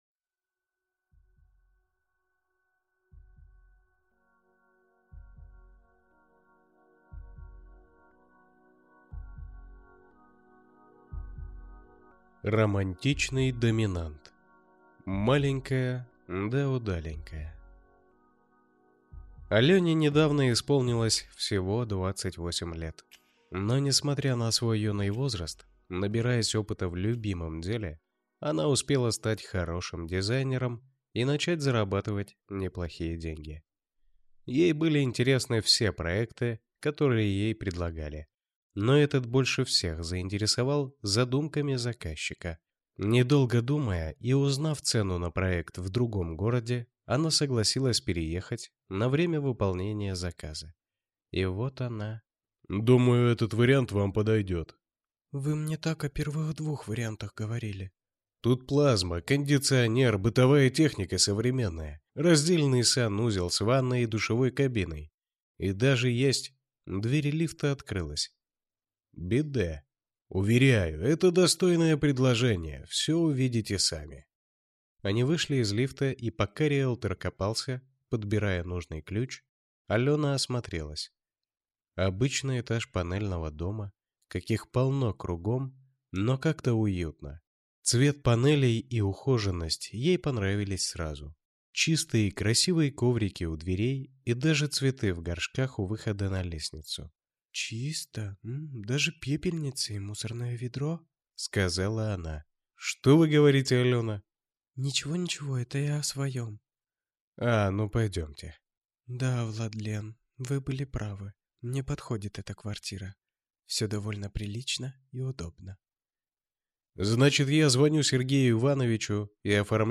Аудиокнига Маленькая, да удаленькая | Библиотека аудиокниг